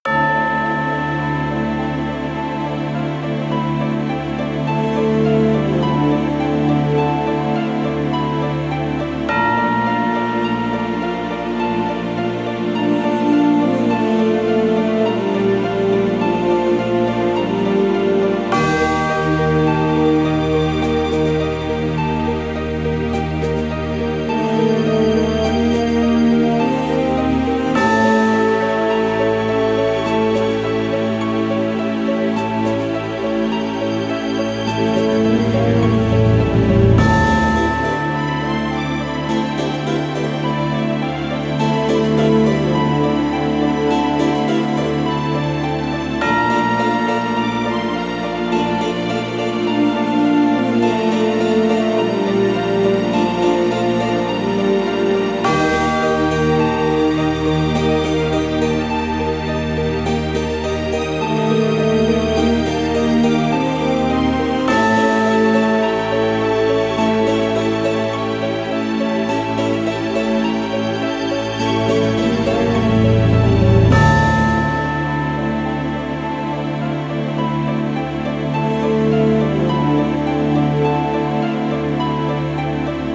イメージ：タイトル 重い   カテゴリ：RPG−テーマ・序盤